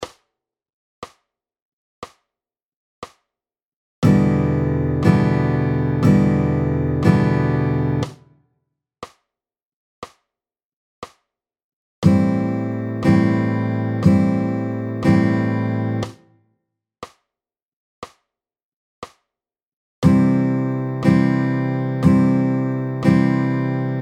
• 指一本で押さえられるEm7
• もう一本の指を加えてAm7
• 更に指を3本に増やしてCコードにチャレンジ
各コード4回弾きましょう。